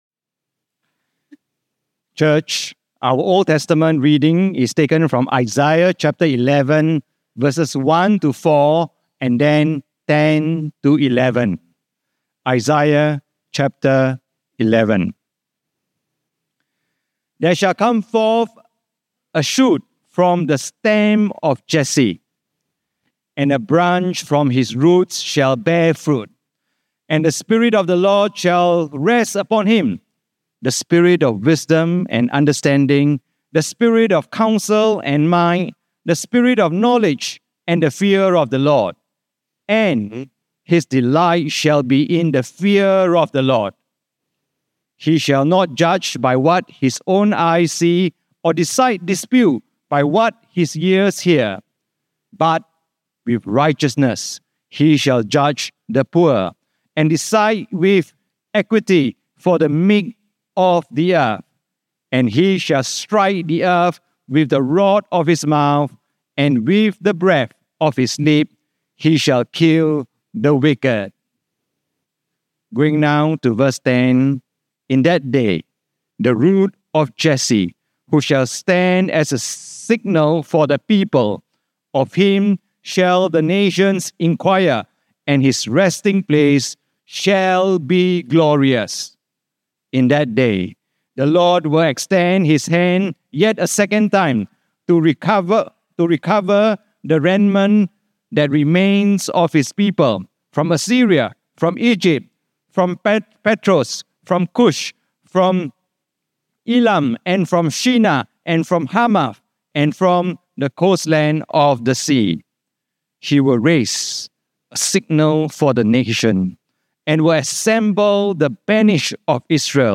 Sermon Archives - St.John's-St.Margaret's Church